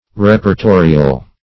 Meaning of reportorial. reportorial synonyms, pronunciation, spelling and more from Free Dictionary.